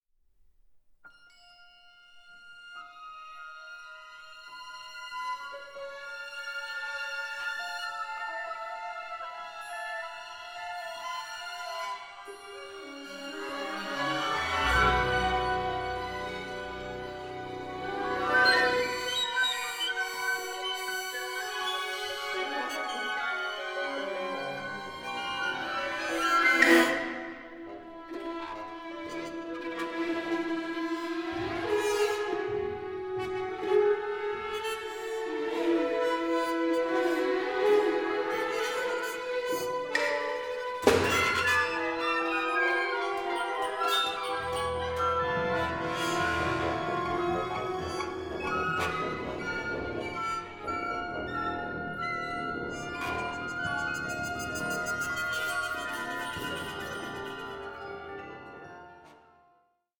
for large orchestra
CONTEMPORARY SOUND WORLD ROOTED IN TRADITION